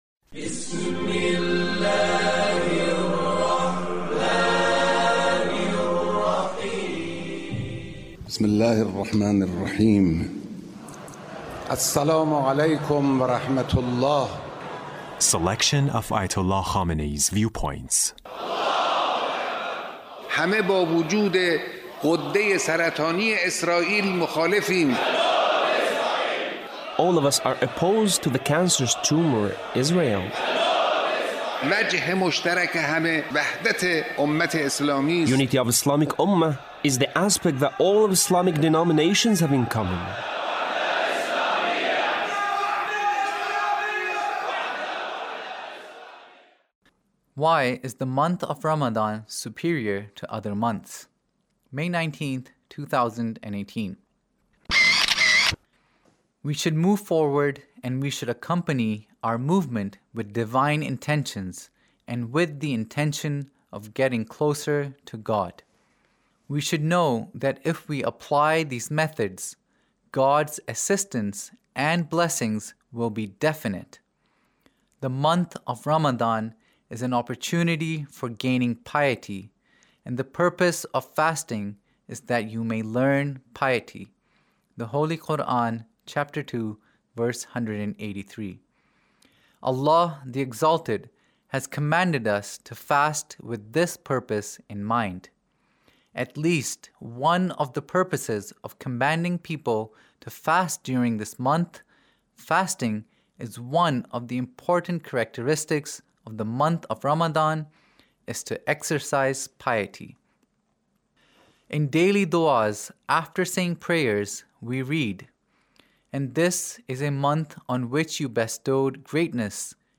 Leader's Speech (1908)